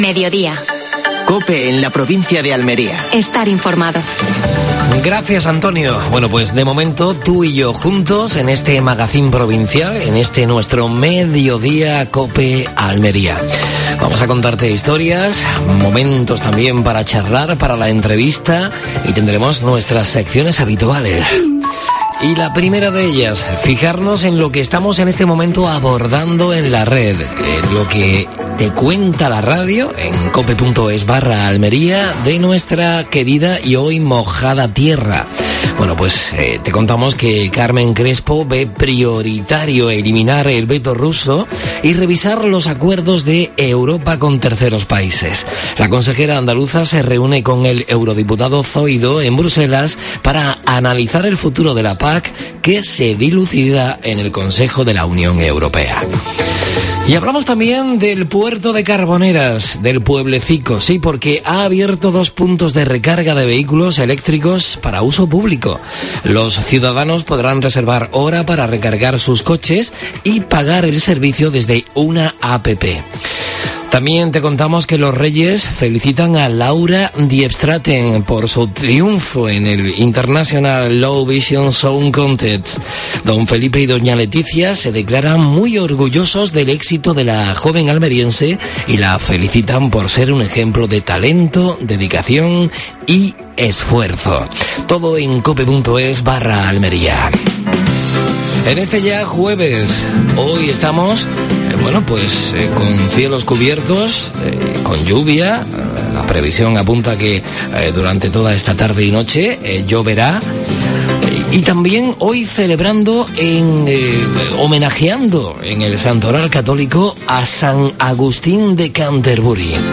AUDIO: Actualidad en Almería. Entrevista